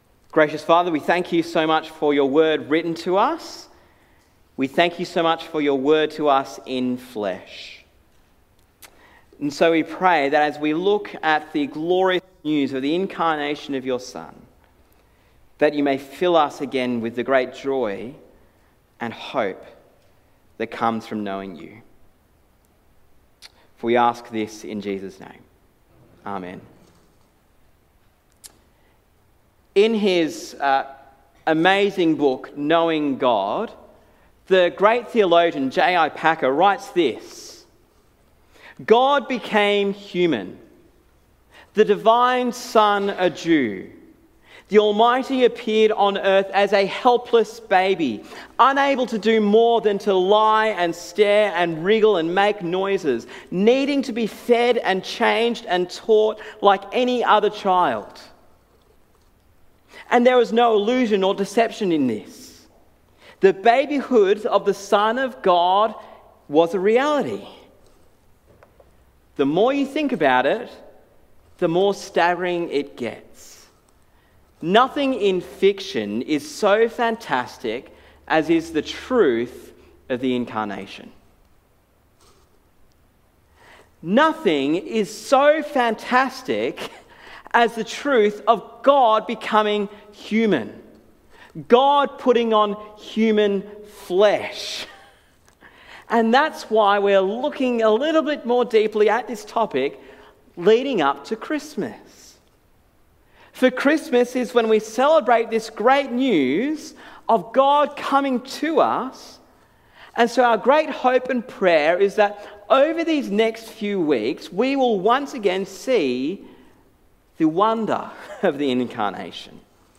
Sermon on Philippians 2:1-11